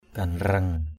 /ɡ͡ɣa-nrʌŋ/ (d.) mão vua, vương miện = mitre royale. ganreng patao Po Romé gn$ p_t< _F@ _r_m^ mão vua Po Romé.